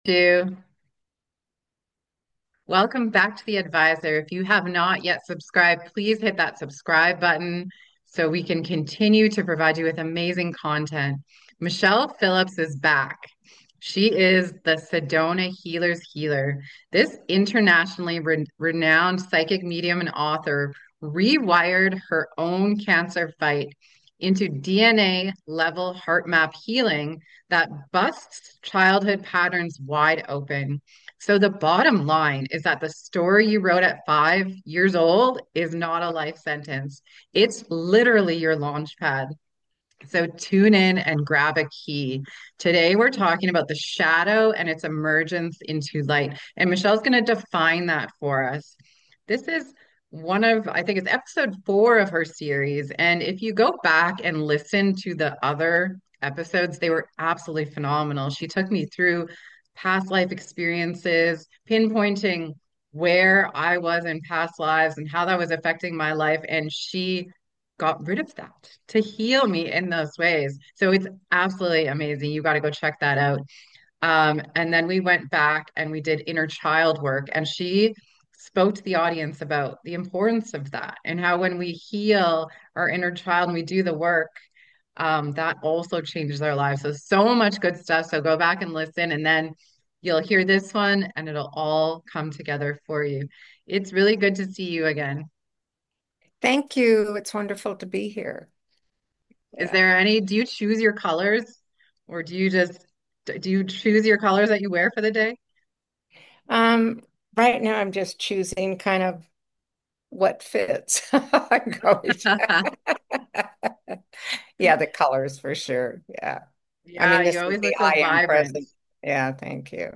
Guided Higher‑Self Meditation